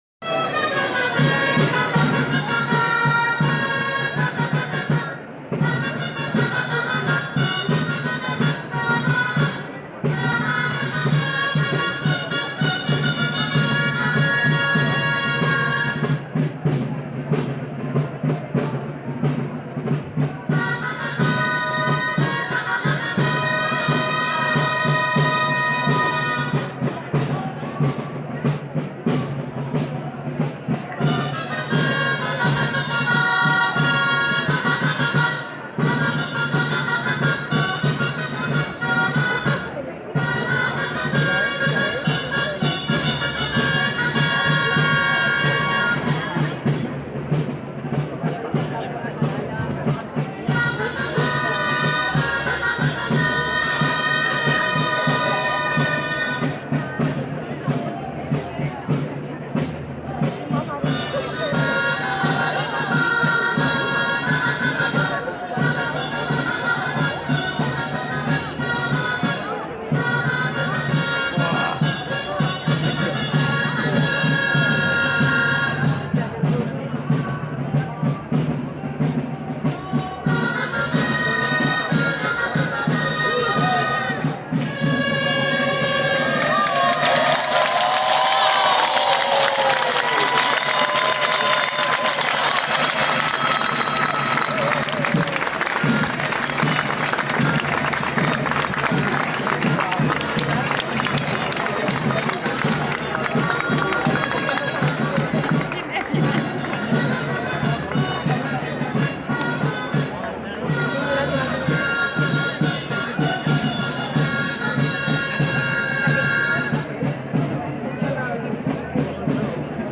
The sound of building a Castell, a human tower, in Tarragona's cathedral square, on 11 September, the National Day of Catalonia, in 2022. Above the hum and chatter of the crowd, gralles (a traditional Catalan wind instrument) and timbals play the “toc de castell”, a responsive tune that accompanies the building and dismantling of the tower. This marks the speed, rhythm and phases of construction, and acts as a guide for the castellers, with the changes in tune informing them of the progress of layers above them. In this recording a round of applause marks the point the "enxaneta" reaches the top and completes the castell, further rounds of applause mark the safe dismantling.